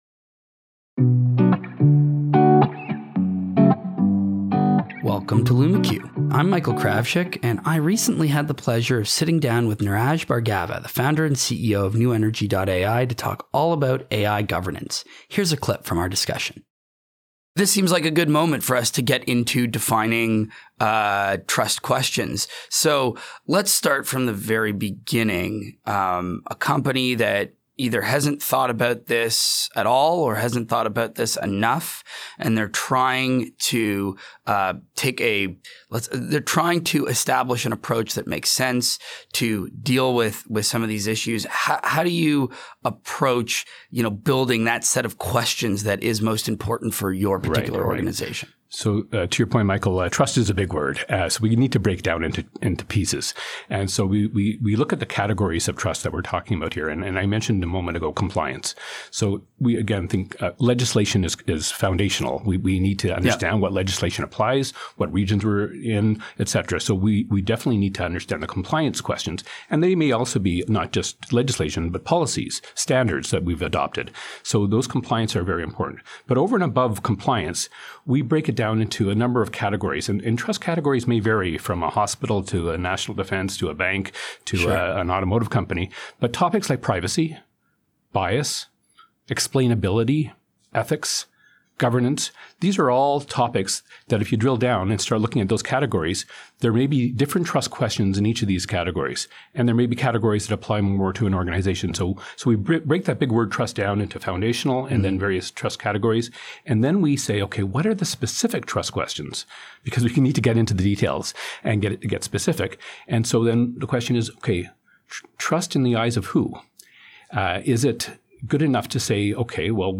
• Practical considerations for putting guardrails around your AI (podcast excerpt)